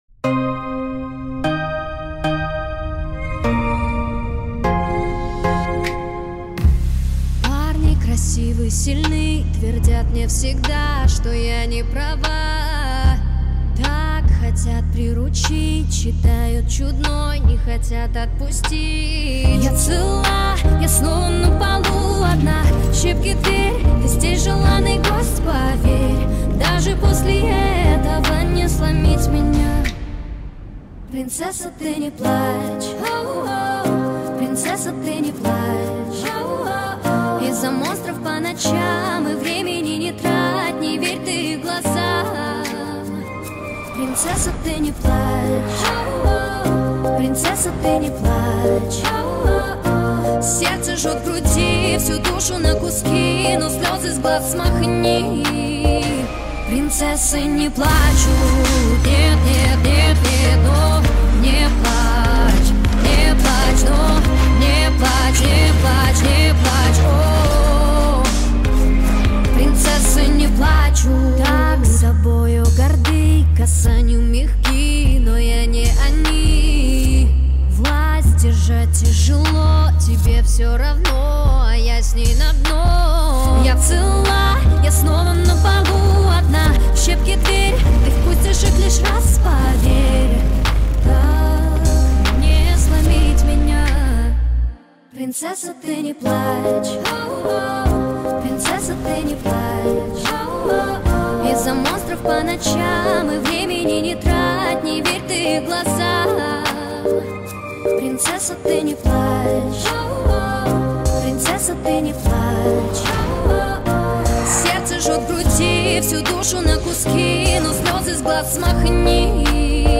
(Russian cover)